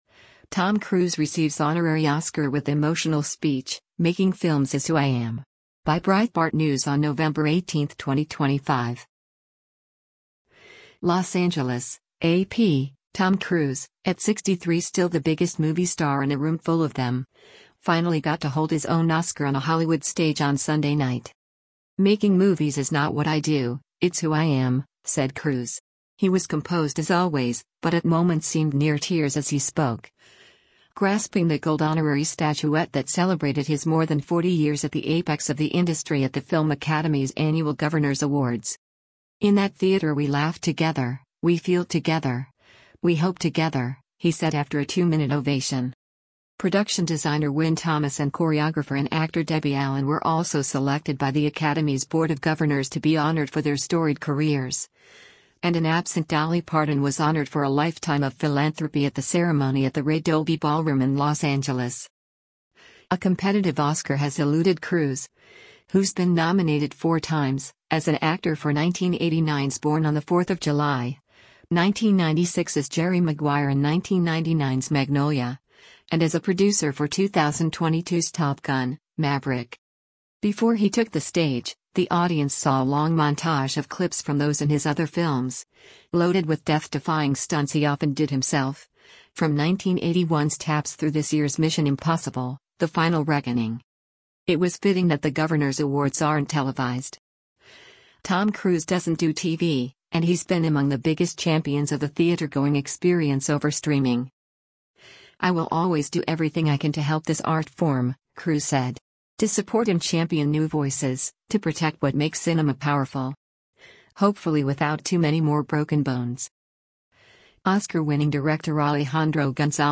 Tom Cruise at The 16th Governors Awards held at The Ray Dolby Ballroom at Ovation Hollywoo
He was composed as always, but at moments seemed near tears as he spoke, grasping the gold honorary statuette that celebrated his more than 40 years at the apex of the industry at the film academy’s annual Governors Awards.
“In that theater we laugh together, we feel together, we hope together,” he said after a two-minute ovation.